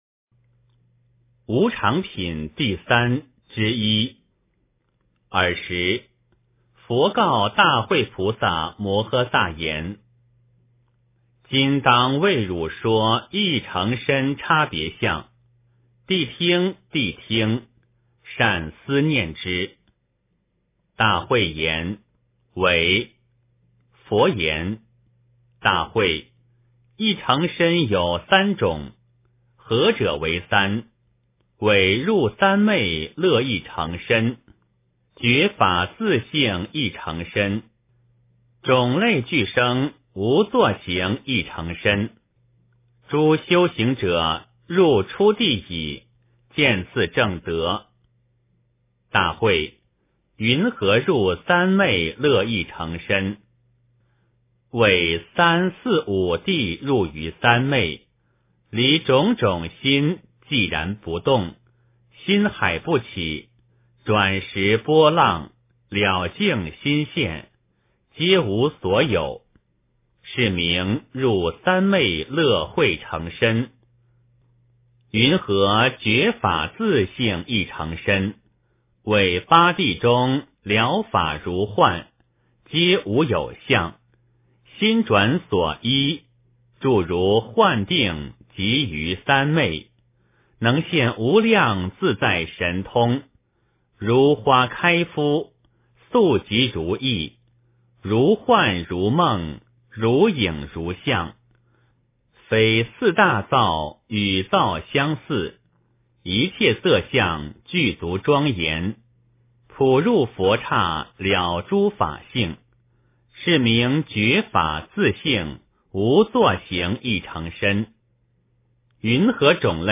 楞伽经（无常品第三之一） - 诵经 - 云佛论坛